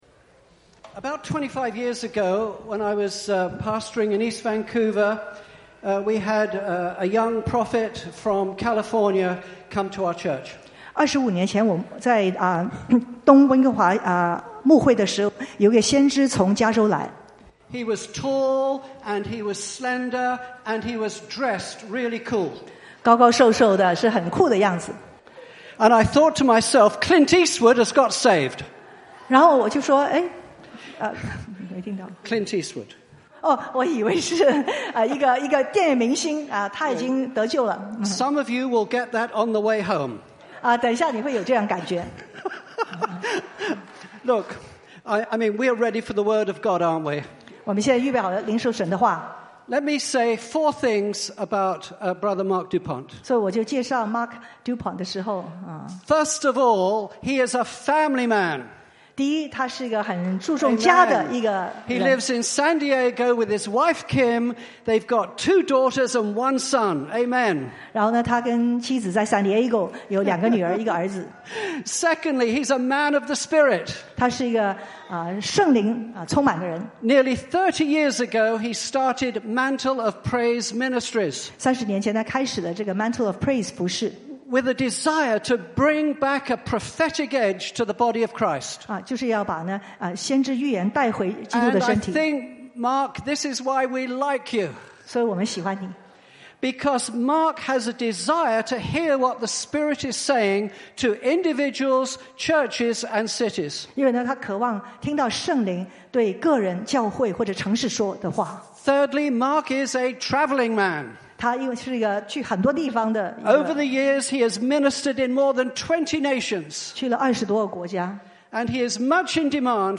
复兴特会一